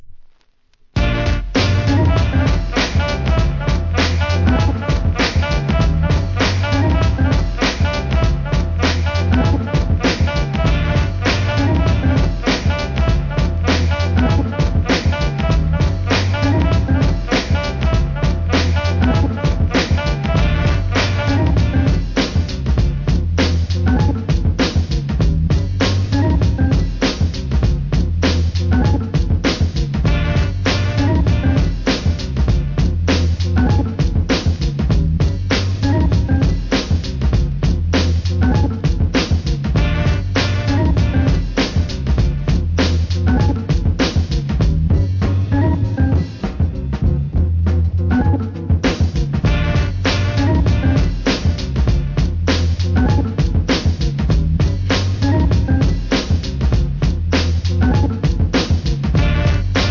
HIP HOP/R&B
らしさ満点なスリリングで危険なBEAT満載です!!